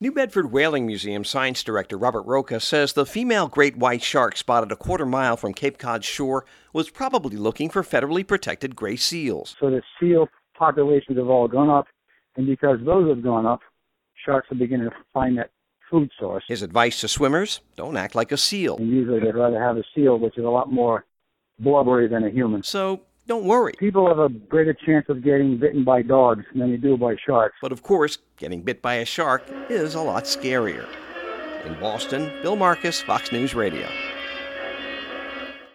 FROM BOSTON.